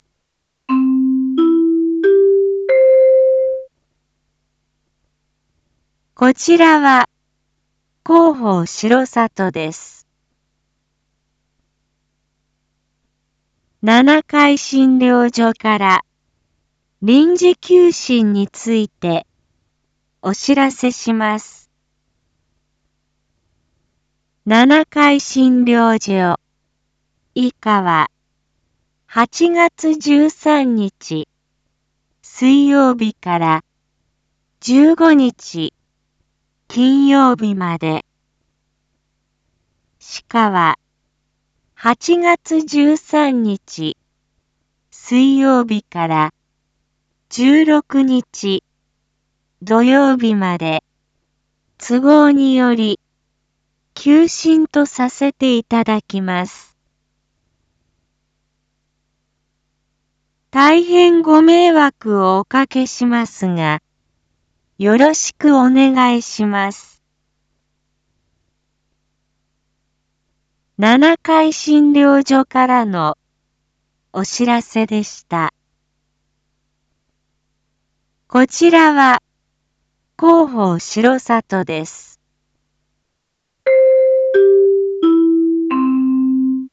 Back Home 一般放送情報 音声放送 再生 一般放送情報 登録日時：2025-08-13 19:01:33 タイトル：R7.8.13七会診療所医科臨時休診 インフォメーション：こちらは広報しろさとです。 七会診療所から臨時休診についてお知らせします。